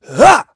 Clause_ice-Vox_Attack5.wav